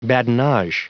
Prononciation du mot badinage en anglais (fichier audio)
Prononciation du mot : badinage